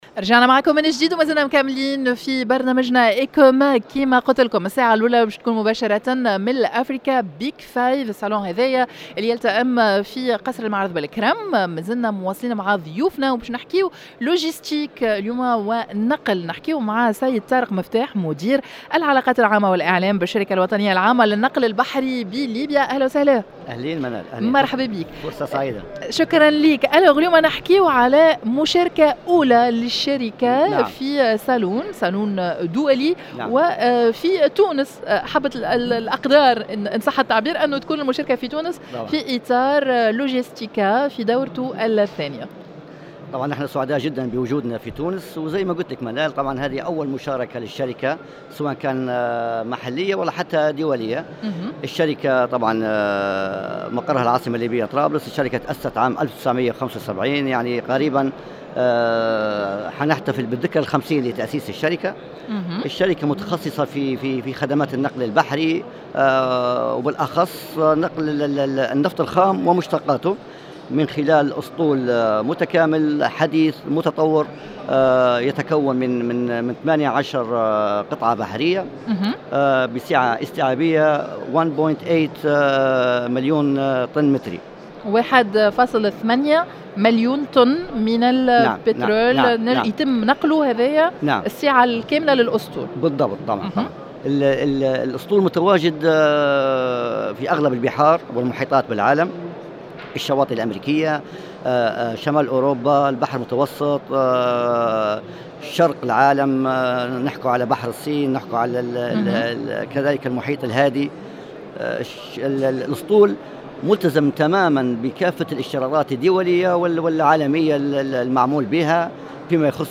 مباشرة من قصر المعارض بالكرم بمناسبة الدورة العاشرة من المعرض الإفريقي للنفط والغاز والطاقات المتجددة « Africa Big 5 »